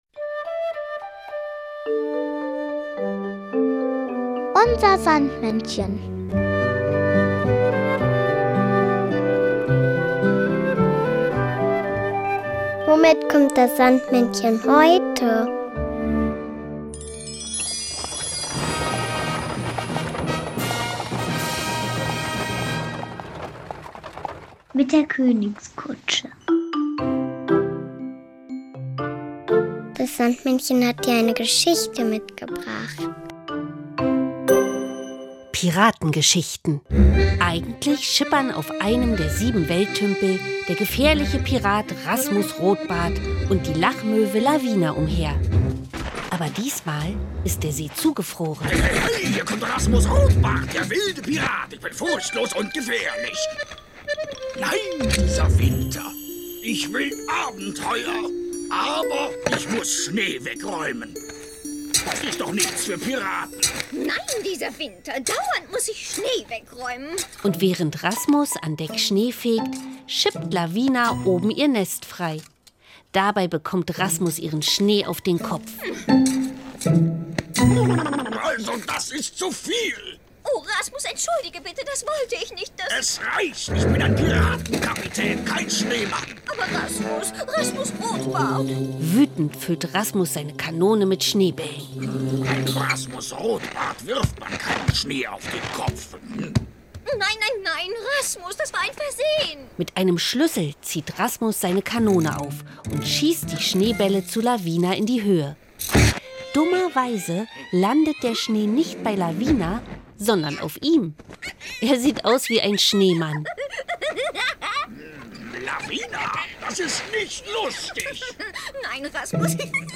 nur diese Geschichte mitgebracht, sondern auch noch das Kinderlied